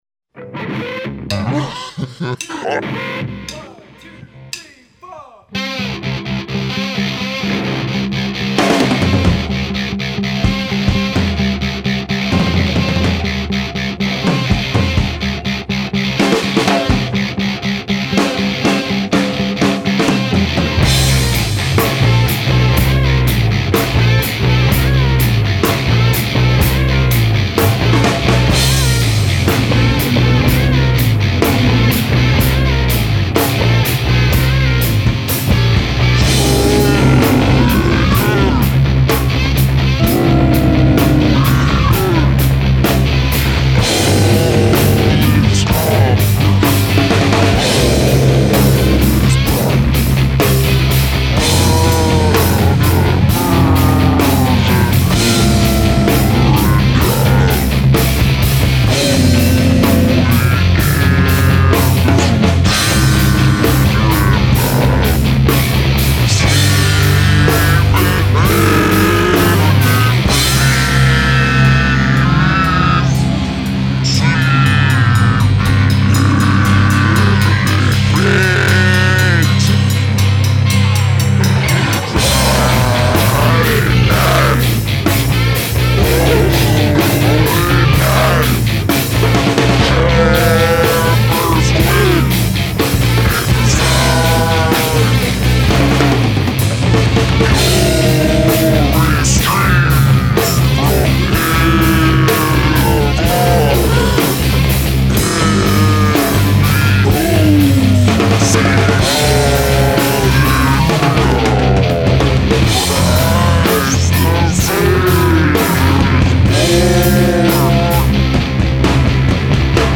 death-metal